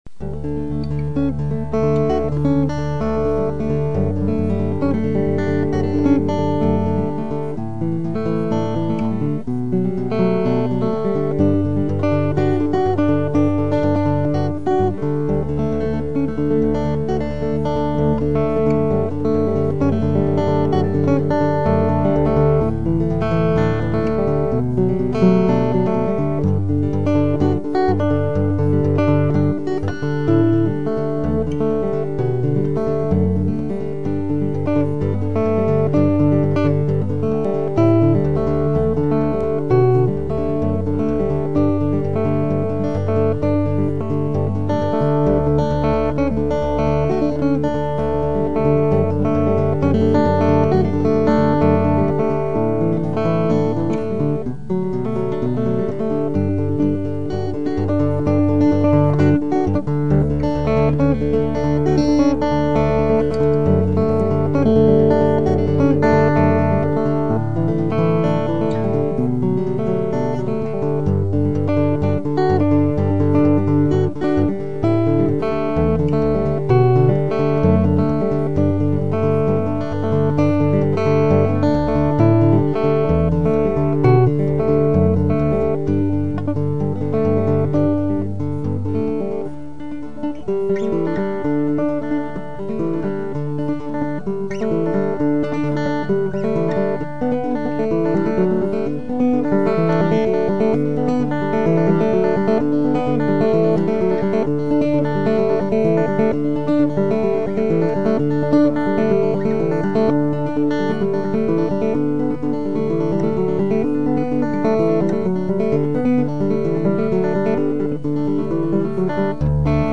Gitarre